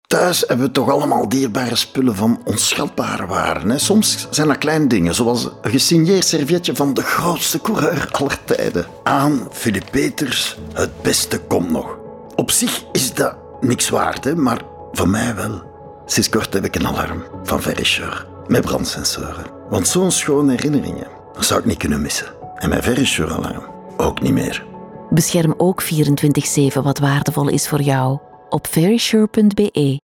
RADIO - SIGNATURE - 30"
250219_Verisure_NL30s_Radio_Signature.mp3
Sound Studio: Raygun